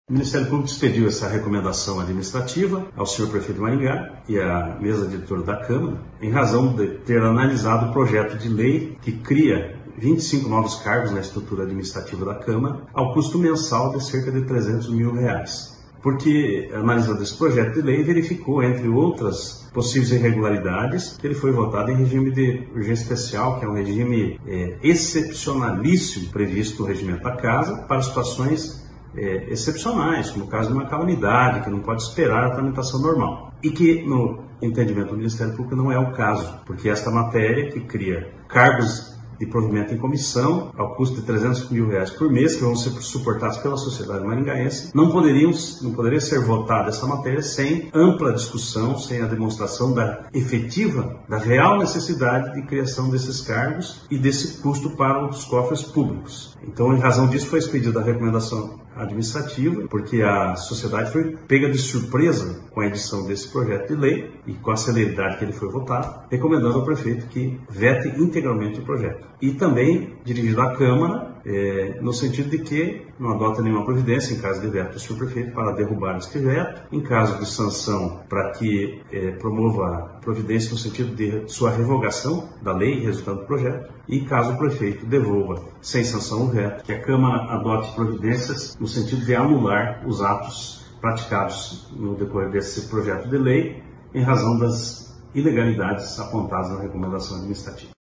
Ouça o promotor Pedro Ivo Andrade: